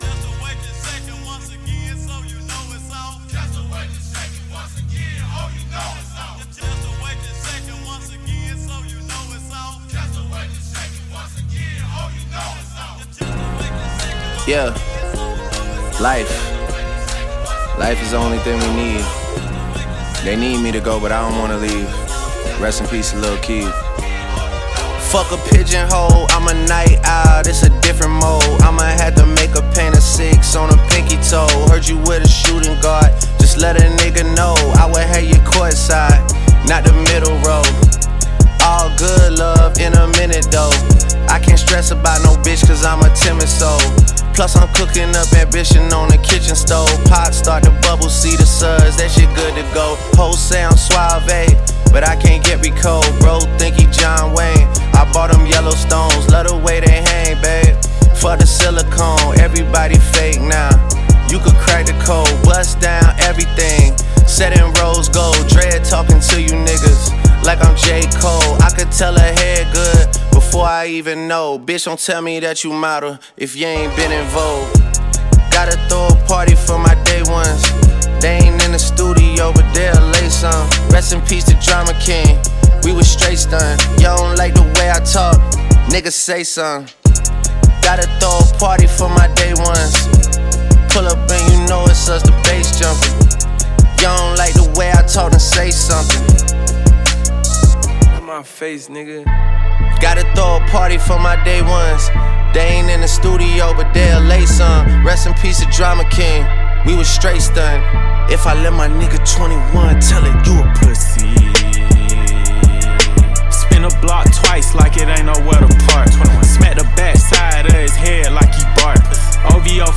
rapper kiêm ca sĩ người Canada